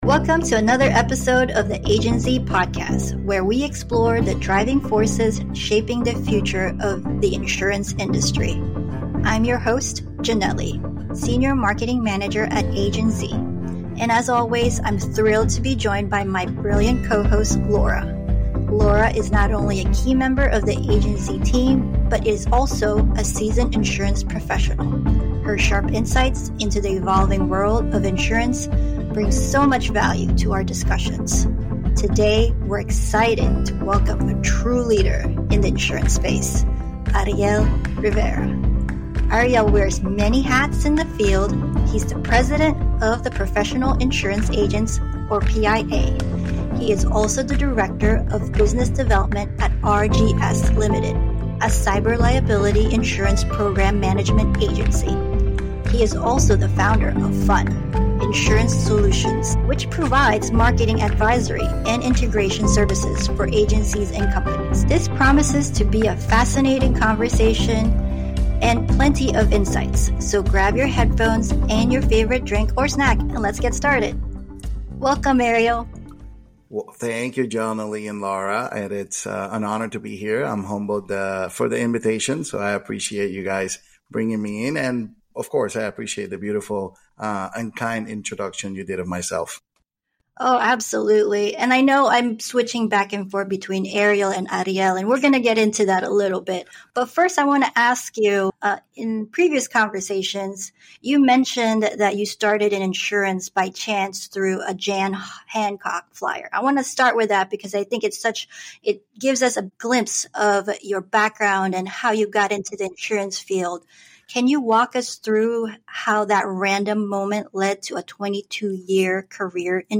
Kiriakou shares the moral and ethical dilemmas he faced, what ultimately led him to speak out, and the personal conse…